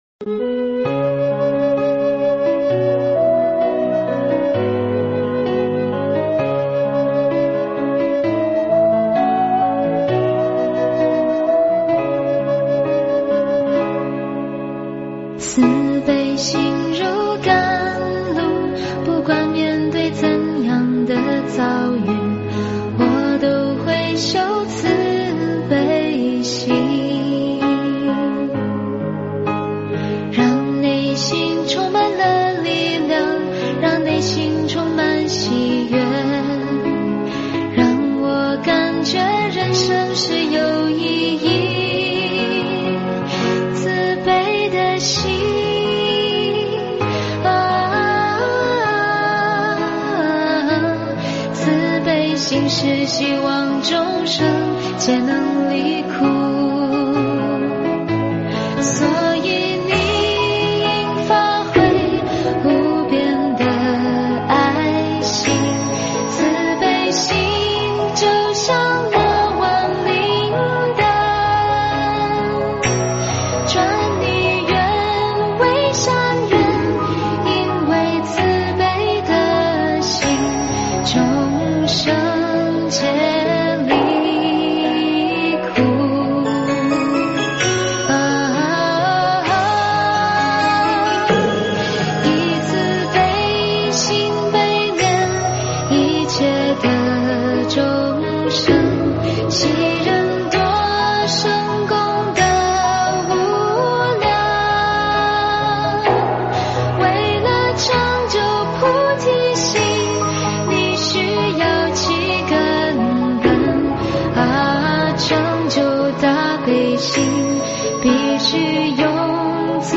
佛音 凡歌 佛教音乐 返回列表 上一篇： 观音行愿曲(童音版